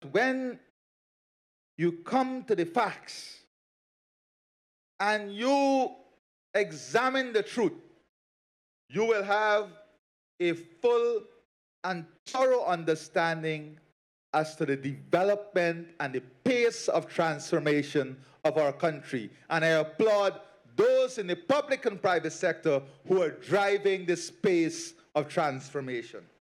President Dr. Mohamed Irfaan Ali at the Opening Ceremony of GuyExpo 2025
President Dr. Irfaan Ali at the opening ceremony on Thursday lauded the work of the public and private sector in driving economic growth in this country.